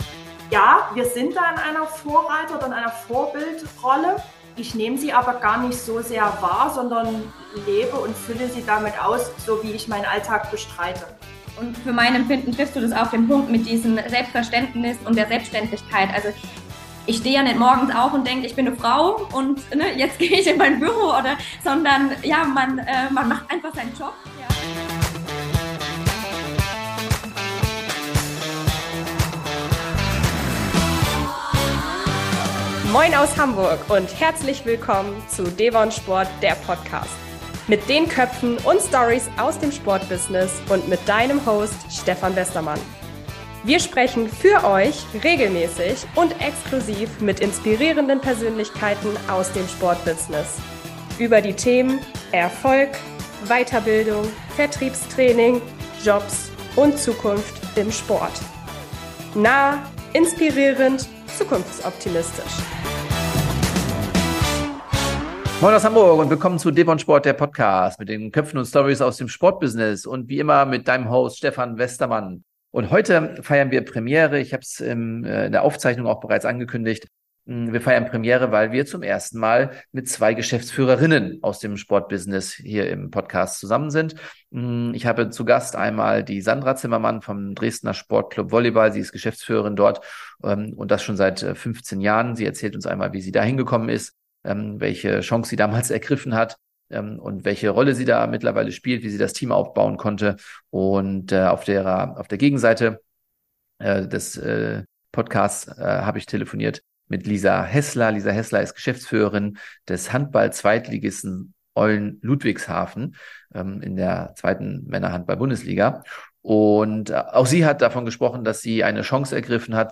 Premiere bei devonSPORT: Heute sprechen wir mit 2 erfolgreichen Geschäftsführerinnen in der Handball und Volleyball Bundesliga!